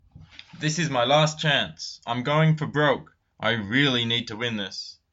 英語ネイティブによる発音はこちらです。